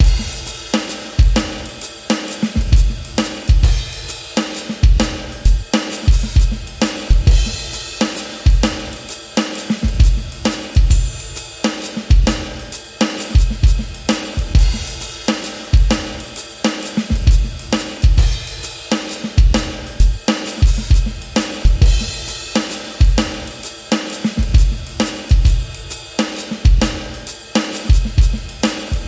描述：用RolandTD 25鼓组演奏和录音。这首曲子里有很多幽灵音符。
Tag: 66 bpm Rock Loops Drum Loops 4.89 MB wav Key : Unknown